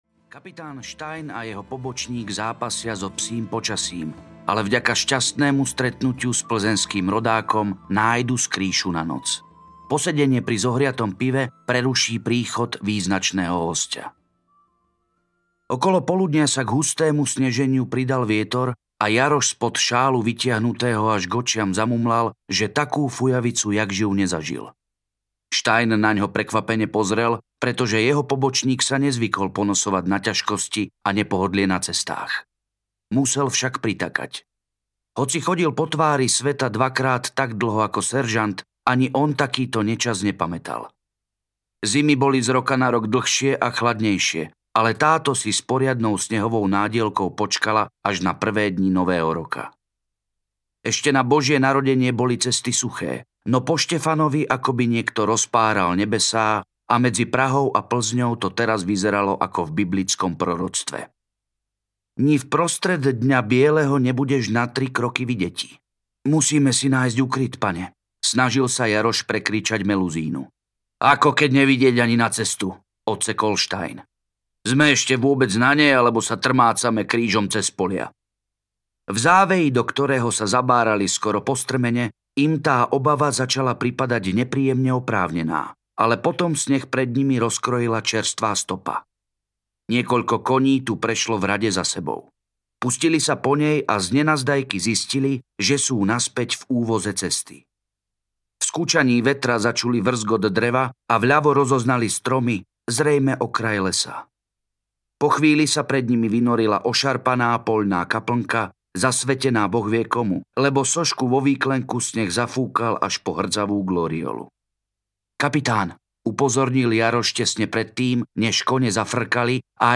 Anjel v podsvetí audiokniha
Ukázka z knihy